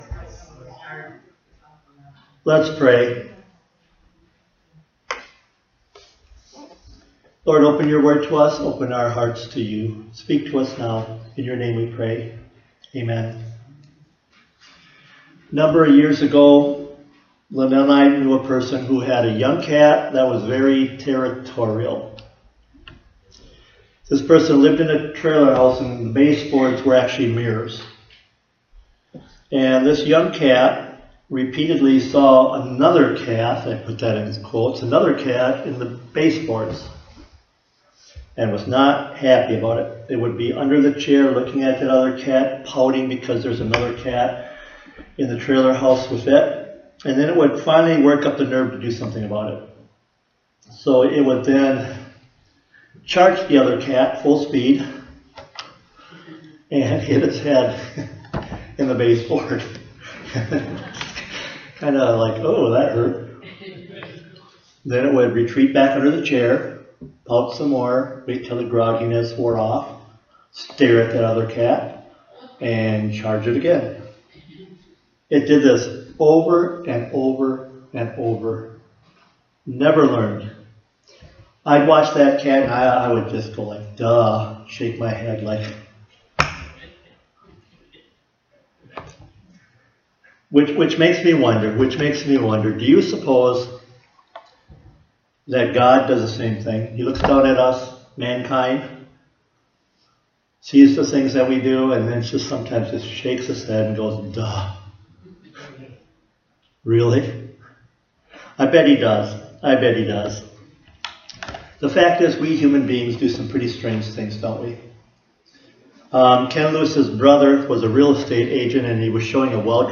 Sermons | Westview Primitive Methodist Church